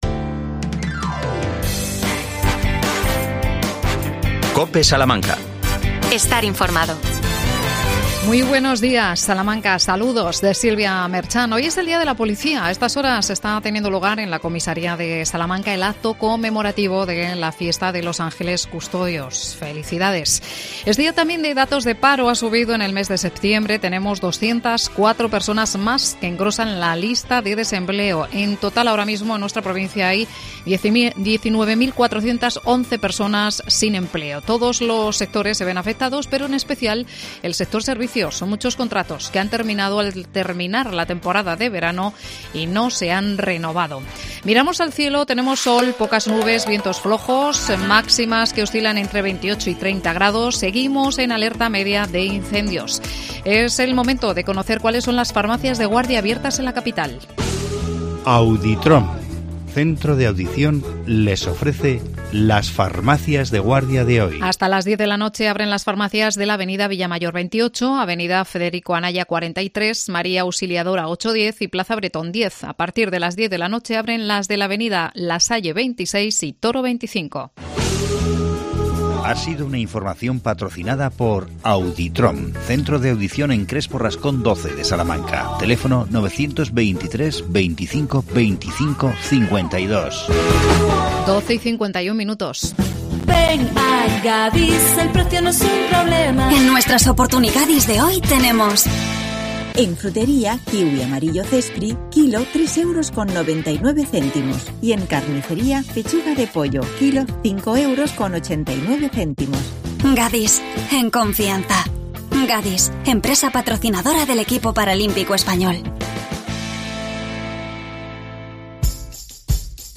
AUDIO: Entrevistamos a la concejala de Participación Ciudadana y Voluntariado Almudena Parres. El tema I Encuentro de Participación Local.